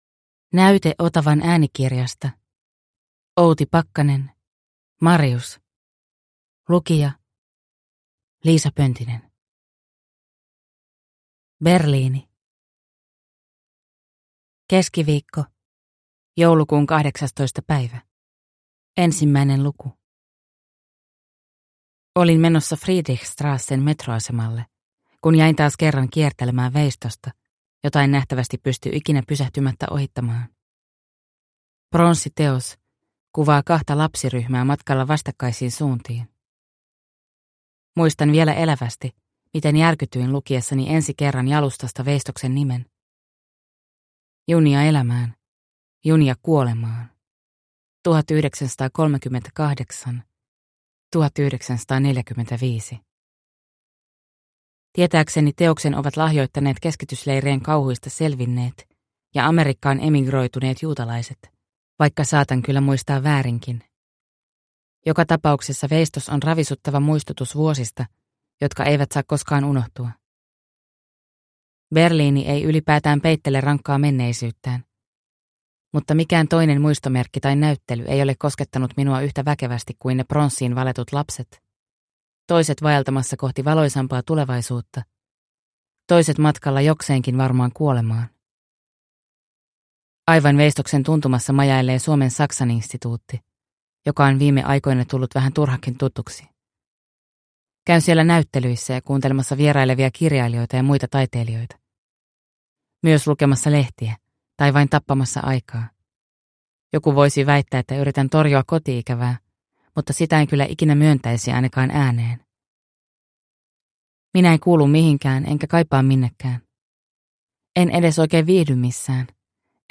Marius – Ljudbok – Laddas ner